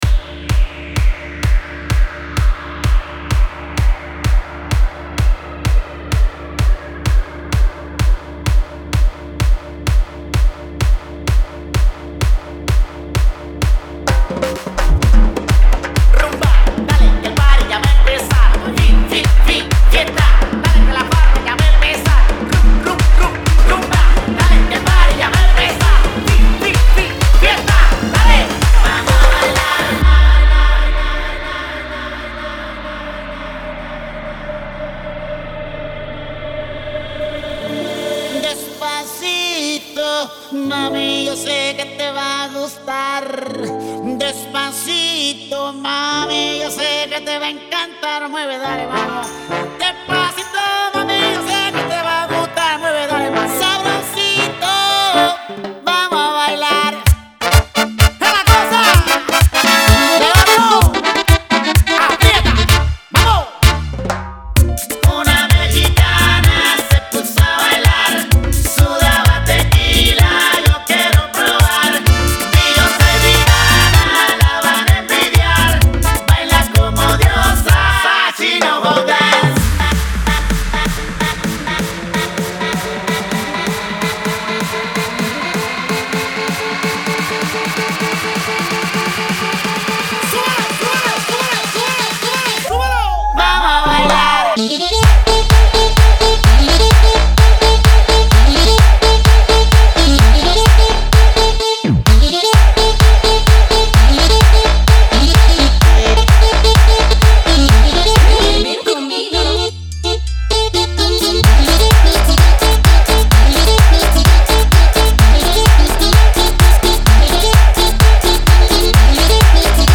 это зажигательный трек в жанре электро-латин